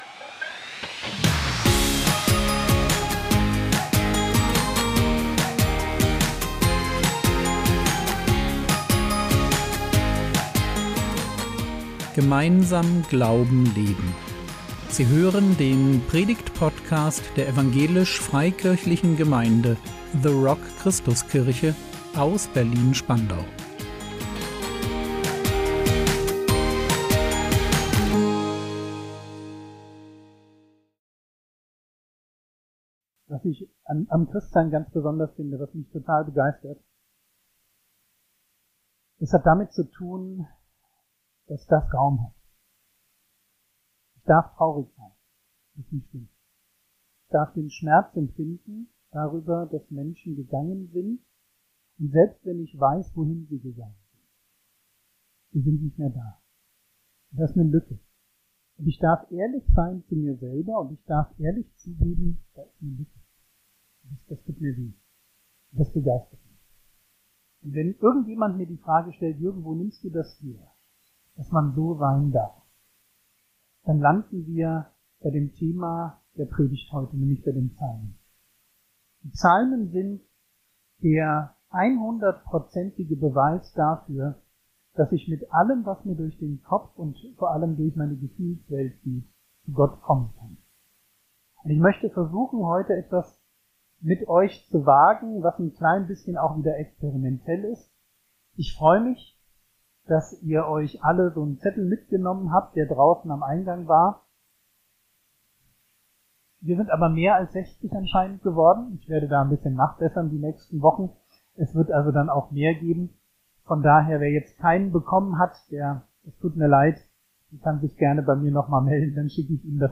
Psalmen - Teil 2 | 23.11.2025 ~ Predigt Podcast der EFG The Rock Christuskirche Berlin Podcast